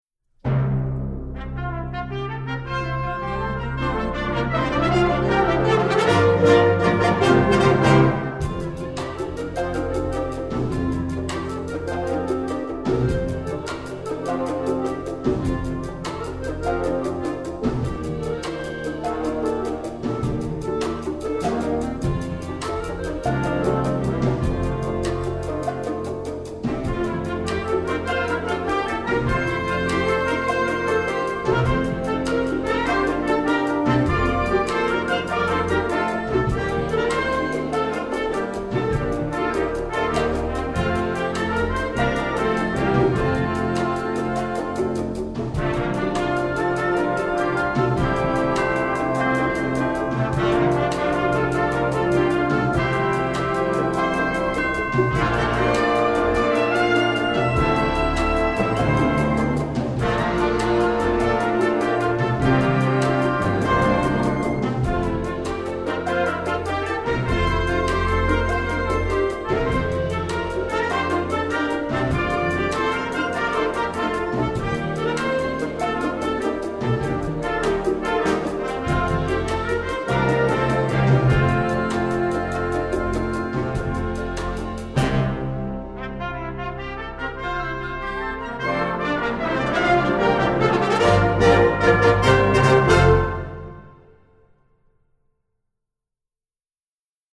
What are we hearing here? multicultural, sacred